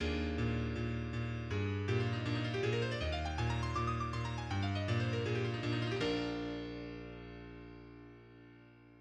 Wolfgang Amadeus Mozart used 128th and 256th notes in his Variations on "Je suis Lindor", K. 354.
Play at eighth note=40 (quarter note=20)
Mozart_256th_notes.mid.mp3